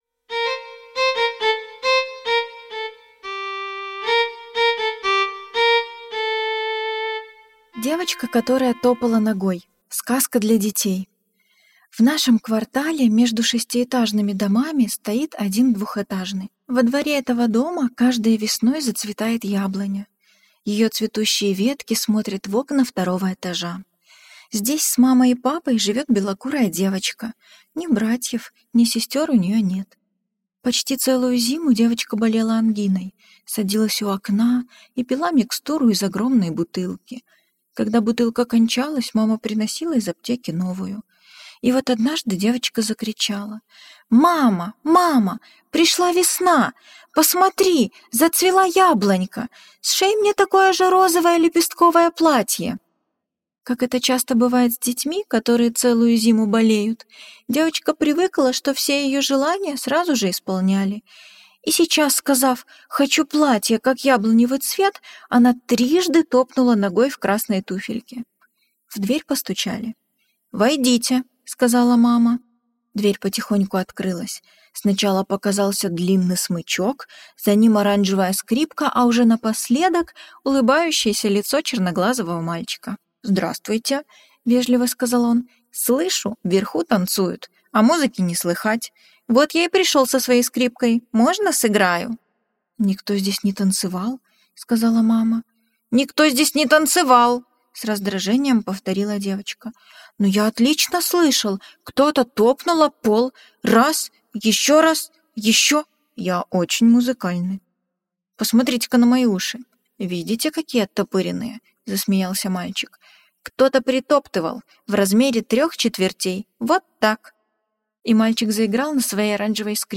Аудиосказка «Девочка, которая топала ногой»